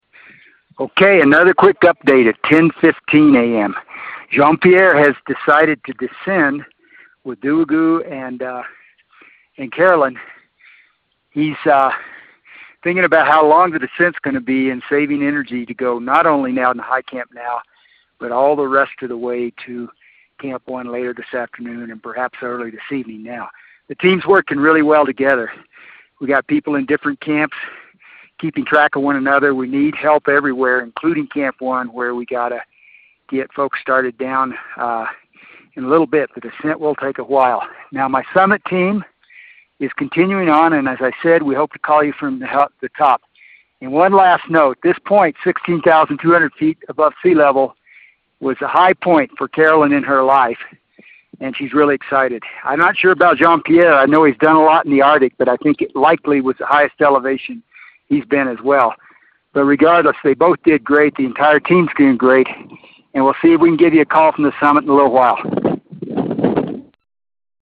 At 16,200 Feet